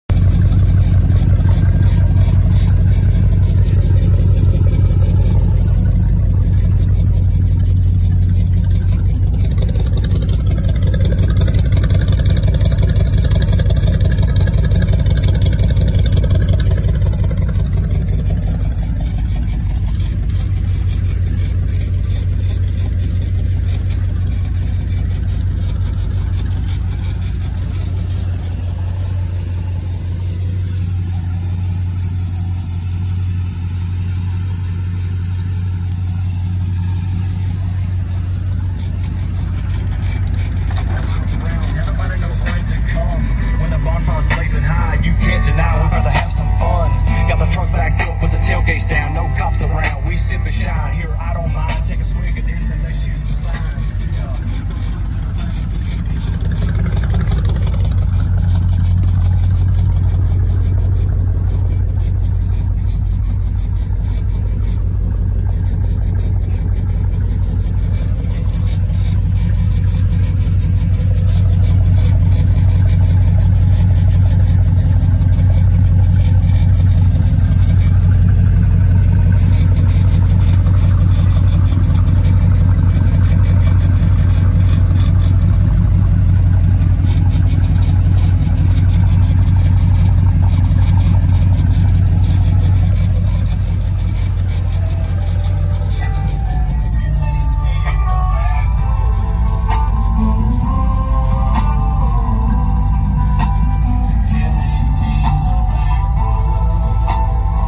My favorite sound, a 1985 Chevy Custom K10 with true dual and Glass Packs on a small block 406 V8